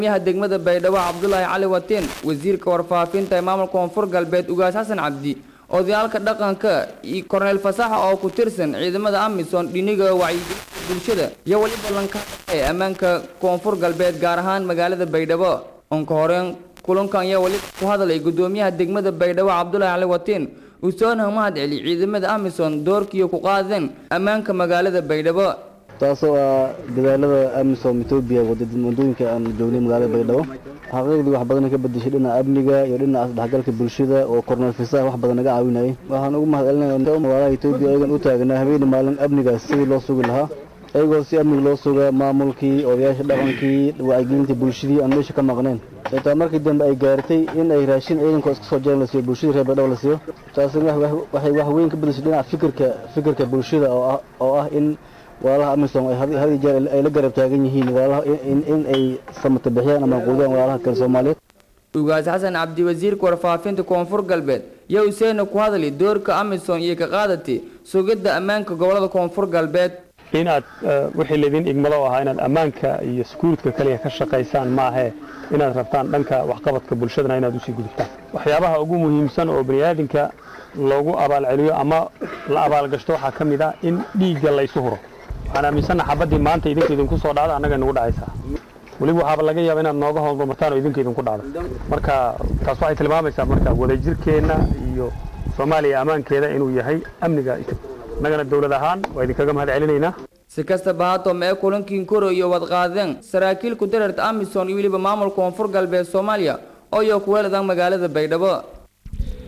Dhageyso: Kulan Looga hadlaayey Amaanka Magaalada Baydhabo.
Amniga-Baydhabo-oo-laga-shirey.mp3